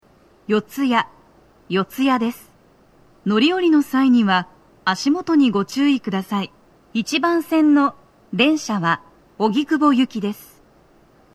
足元注意喚起放送が付帯されています1番線を中心に、時間調整する場合が多く、多少の粘りでフルは狙えます。
女声
到着放送1
TOA天井型()での収録です。